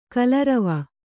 ♪ kalarava